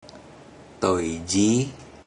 Click each Romanised Teochew word to listen to how the Teochew word is pronounced.
toi20ji2.mp3